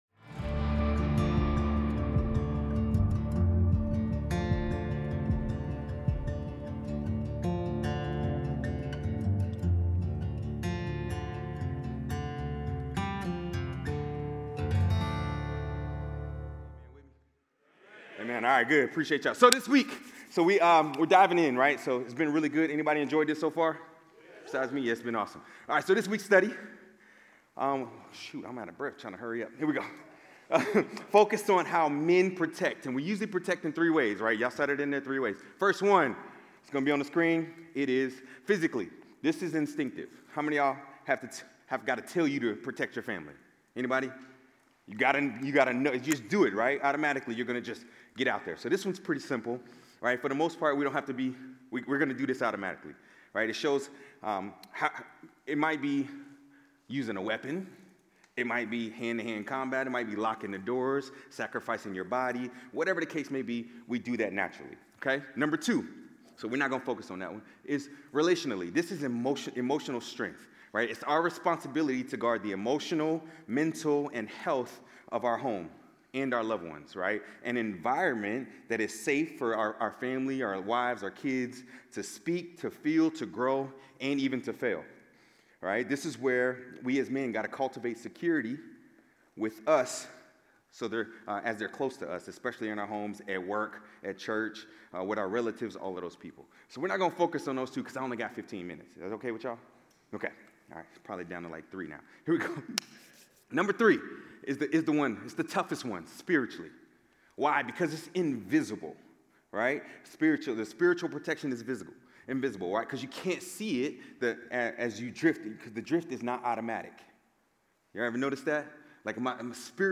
Guest Teacher